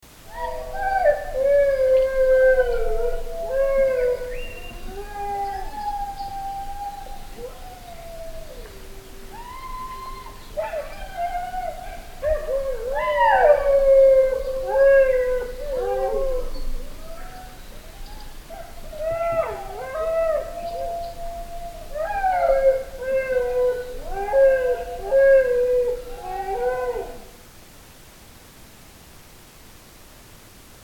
Howling Australian Dingoes